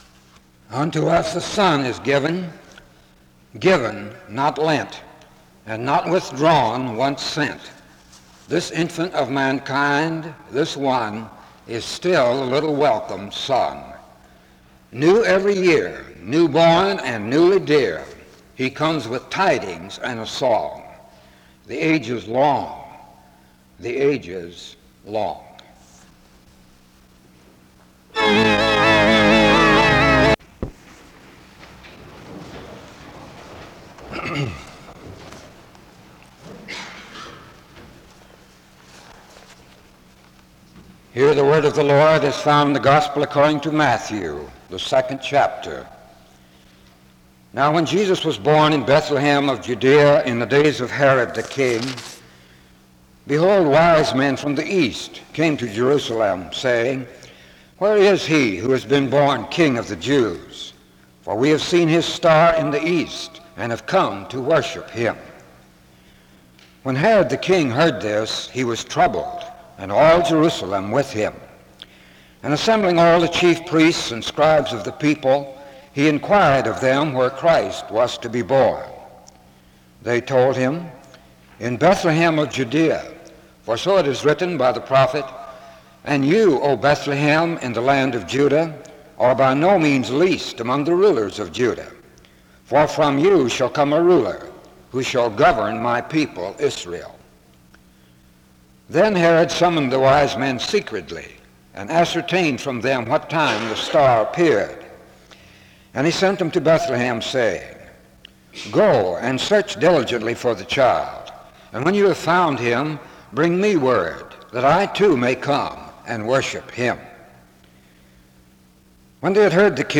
The service begins with a poem (0:00-0:28).
A prayer is then offered (2:47-4:28).